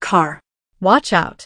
audio_car_watchout.wav